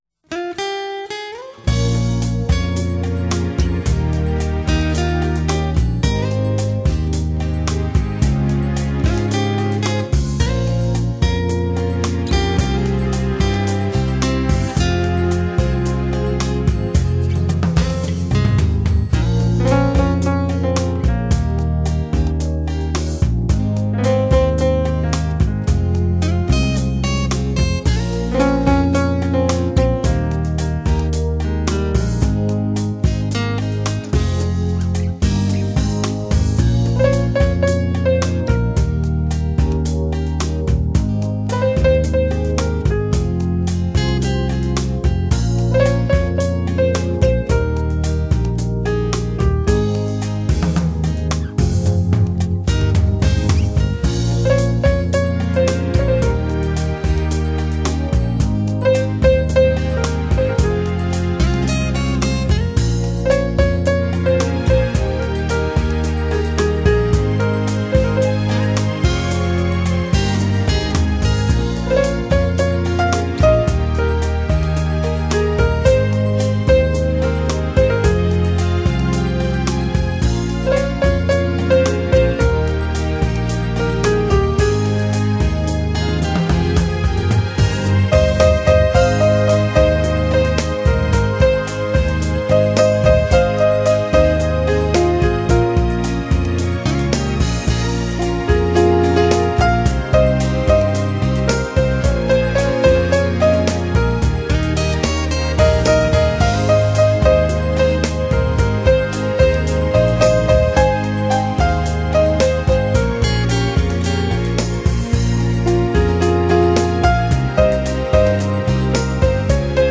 钢琴独奏
吉它伴奏
萨克斯伴奏
马头琴伴奏
小提琴伴奏
双簧管伴奏
长笛伴奏
小号伴奏
二胡伴奏
琵琶伴奏
古筝伴奏